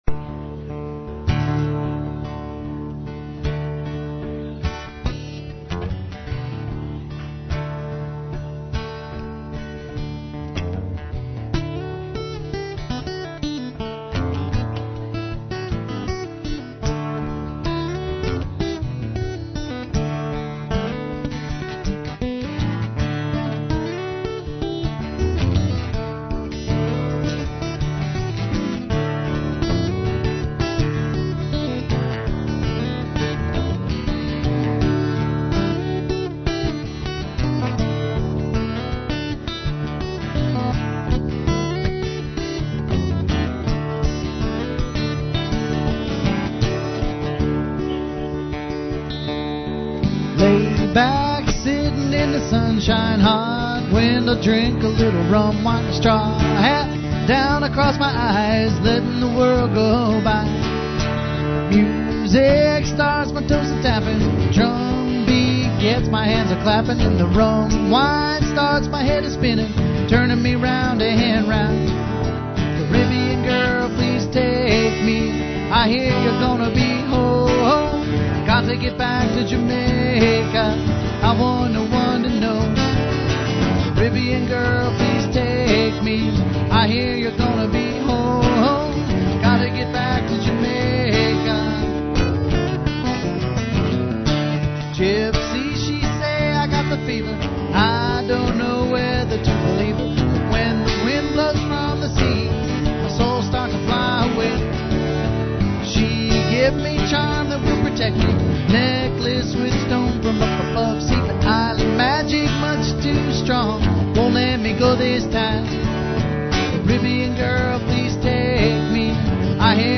Live Tunes